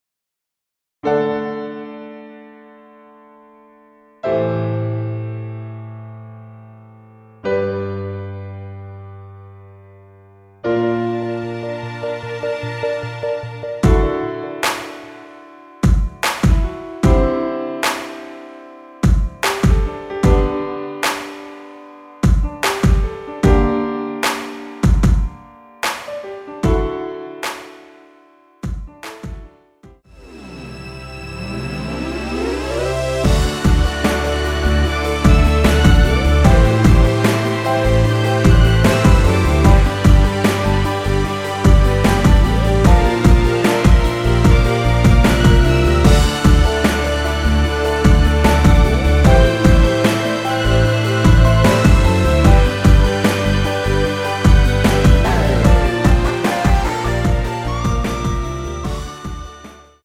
원키에서(-7)내린 MR입니다.
남성분이 부르실수 있는키로 제작 되었습니다.(미리듣기 참조)
앞부분30초, 뒷부분30초씩 편집해서 올려 드리고 있습니다.